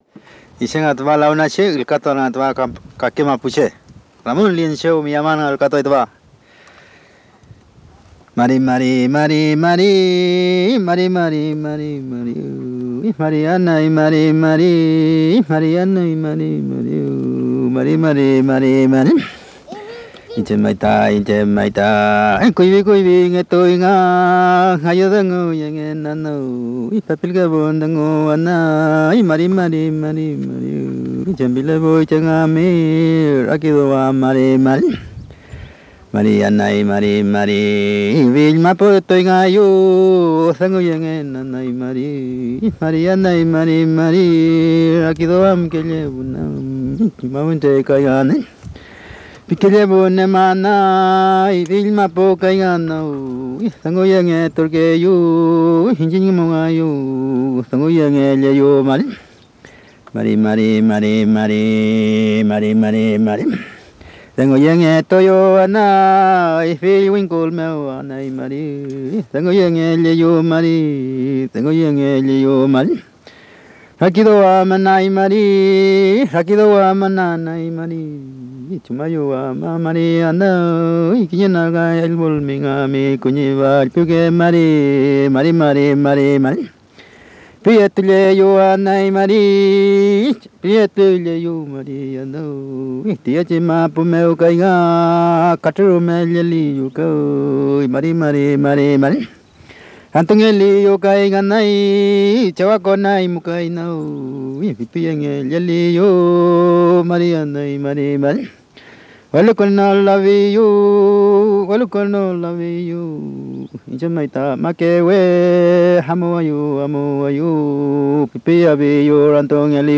Música mapuche (Comunidad Laguna, Lumaco)
Música vocal
Música tradicional